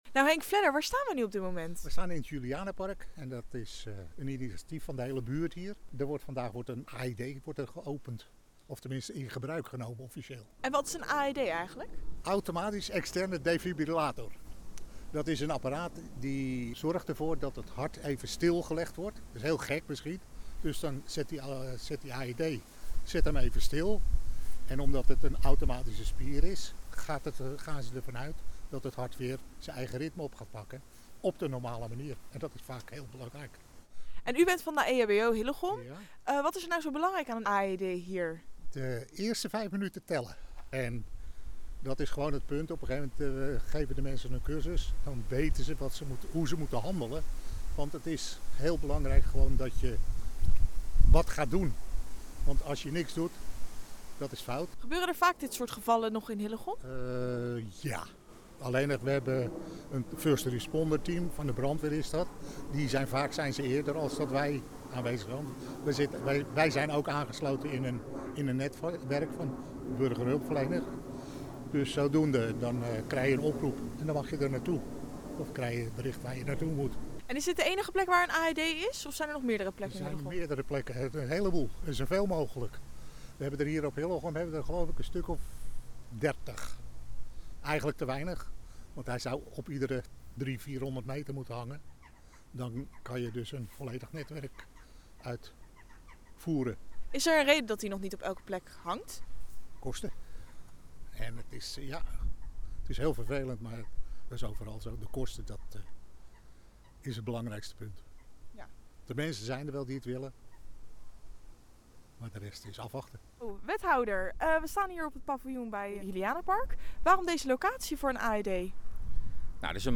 Hieronder de radioreportage: